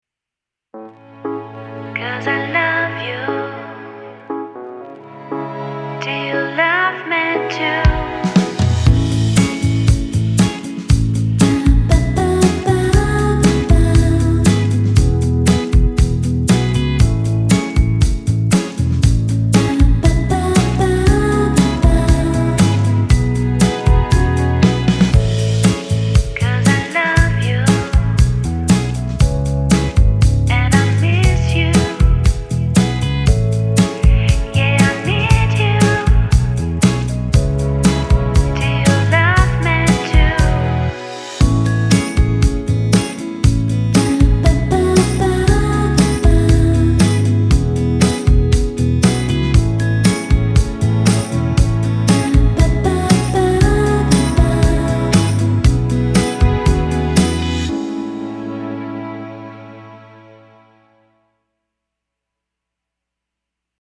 Identité sonore